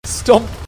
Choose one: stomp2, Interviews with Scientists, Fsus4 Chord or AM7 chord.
stomp2